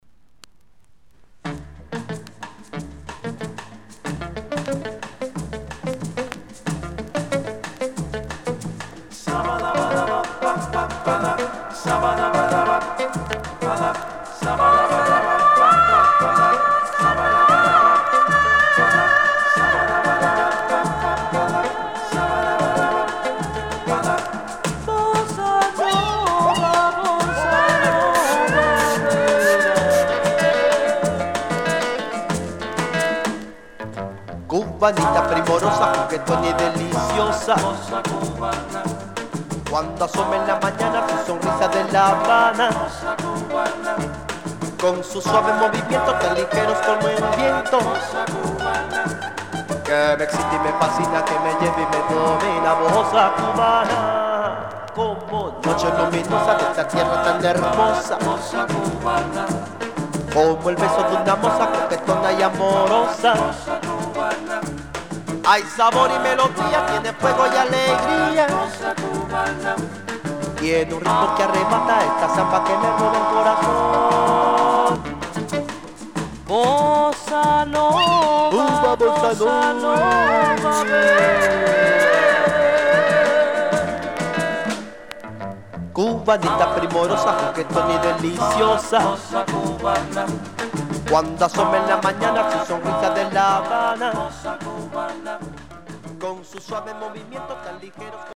キューバン・ドゥーワップのコーラス・グループ
ルンバ、カリプソ、バラード以外にサンバ、ボサノバなどリズムのバリエーションが増している。
当時、キューバで一番の人気バンドの底なしに楽観的な音作りがキューバの当時の雰囲気を伝えてくれる。